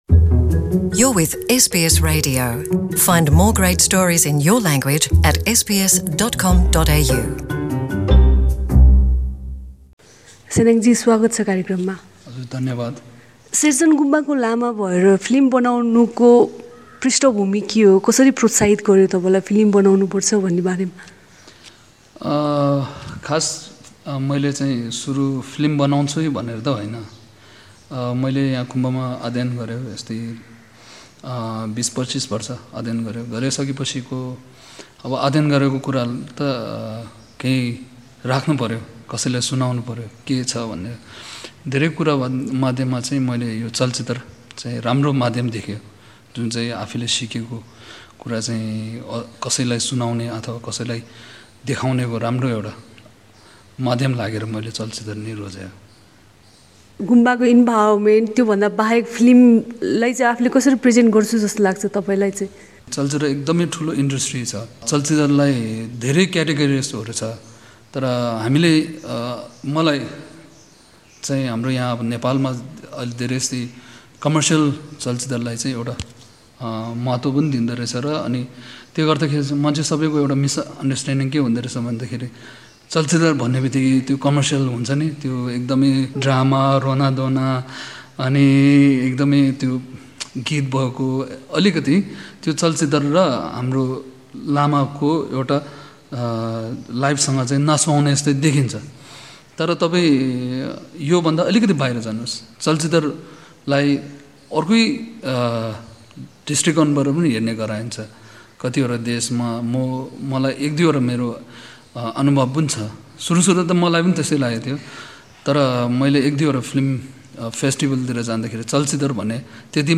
Nepali film director